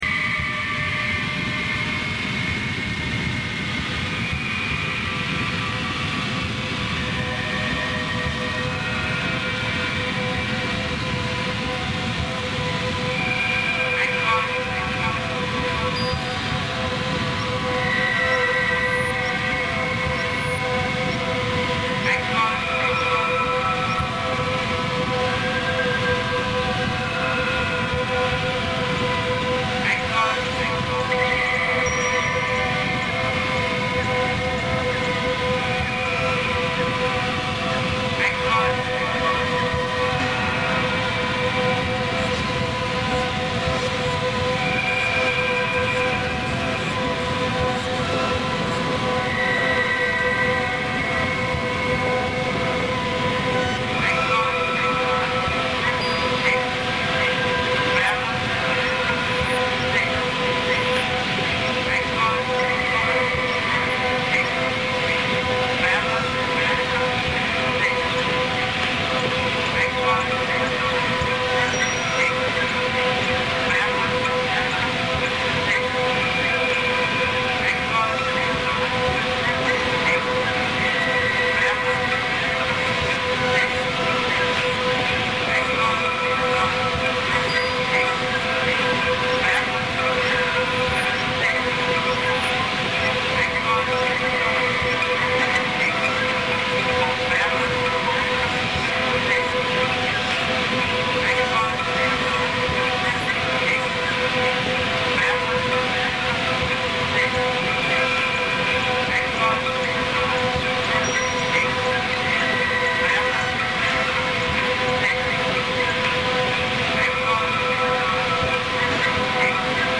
I was attracted to the age and broken nature of this recording and wanted to use the noise as a feature. All the sounds featured originate from the original, however mangled they have become. One could could hear all the layers of noise to be the 100+ years that have past since the recording was captured - with the original sounds still trying to break through.
Balangi (xylophone) duet